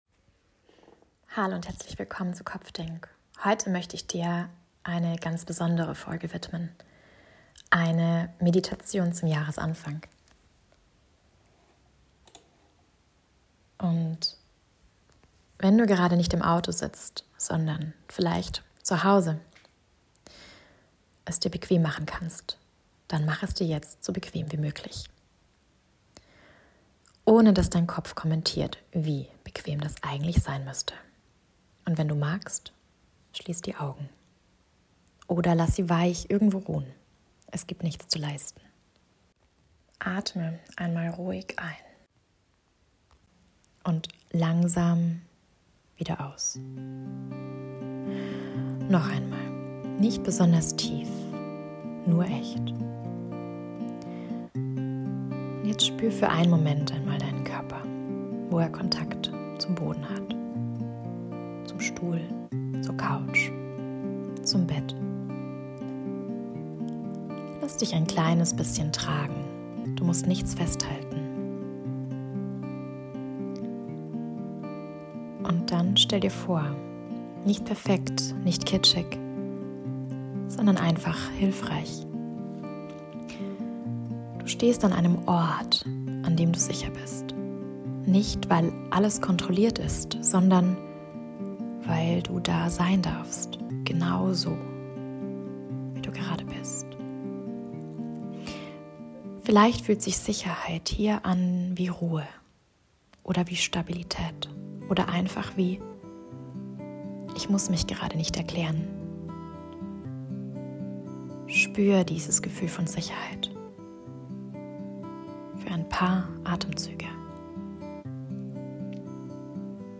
Eine kleine Neujahrsmeditation für dich